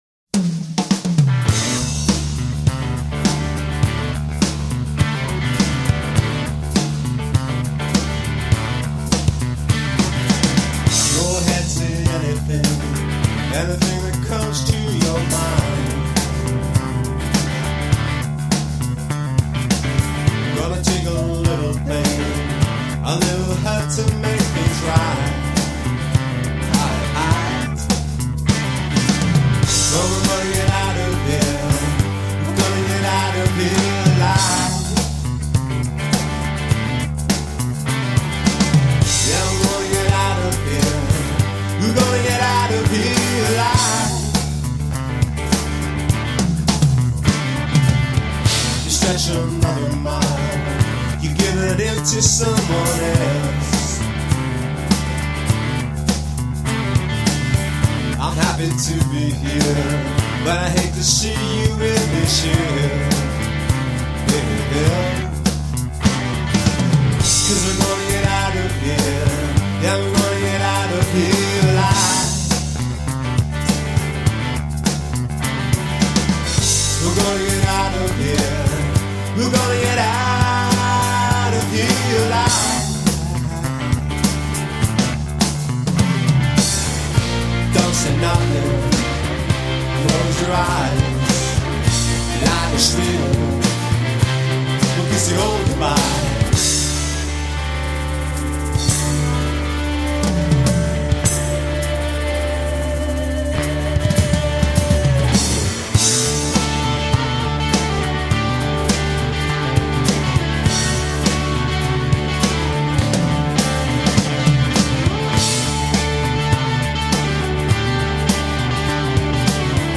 7 slices of raw guitar pop.
Tagged as: Alt Rock, Other